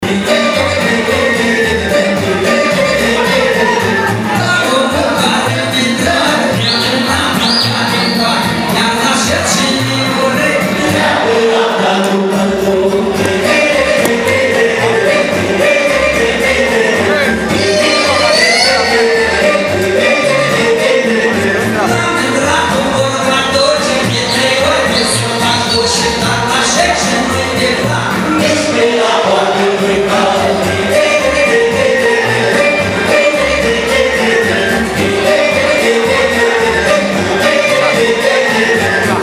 Joi seară, la complexul Ariata Events din Șcheia a avut loc primul concert al ansamblului Plăieșii din Republica Moldova.
Cântecele s-au bucurat de mare succes, fiind aplaudate la scenă deschisă de public.